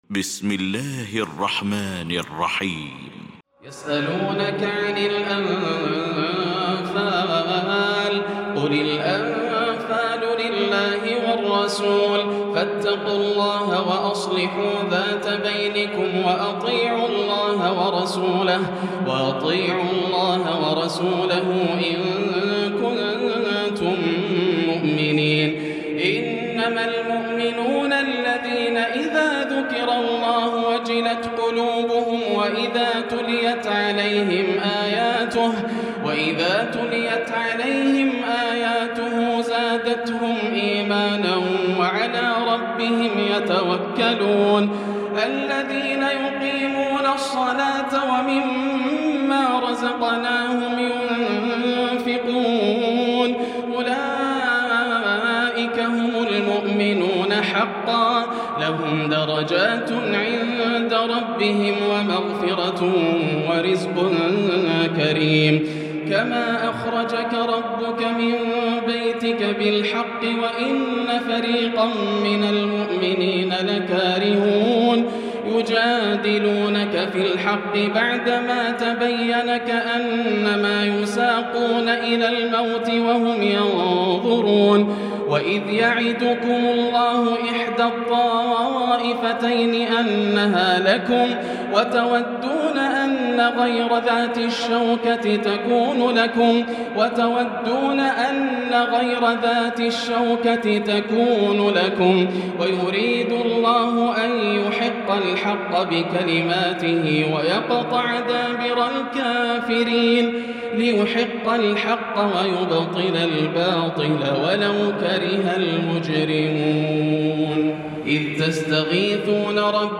المكان: المسجد الحرام الشيخ: سعود الشريم سعود الشريم فضيلة الشيخ ياسر الدوسري الأنفال